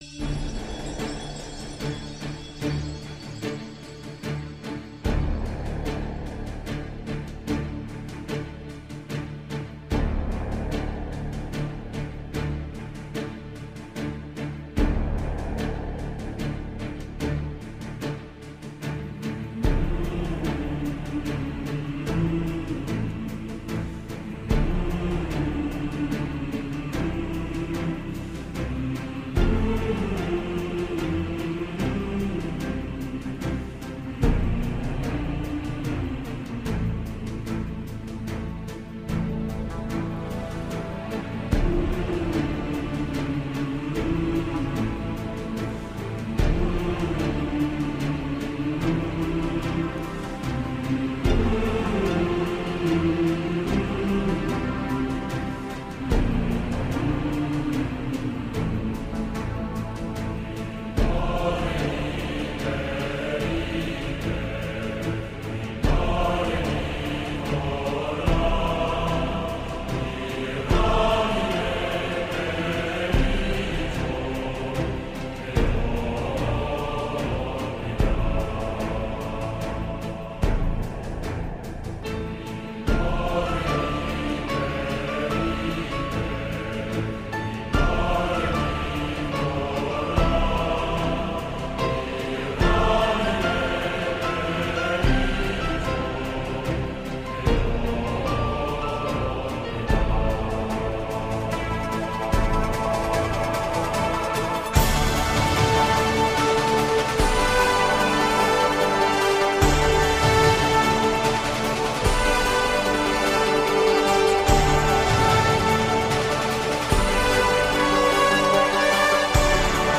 涉及分类：电子音乐 Electronica
使用乐器：键盘 Keyboards  钢琴 Piano  合成器 Synthesizer